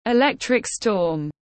Bão tố có sấm sét tiếng anh gọi là electric storm, phiên âm tiếng anh đọc là /ɪˌlek.trɪ.kəl ˈstɔːm/.
Electric storm /ɪˌlek.trɪ.kəl ˈstɔːm/